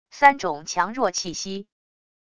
三种强弱气息wav音频